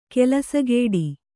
♪ kelasagēḍi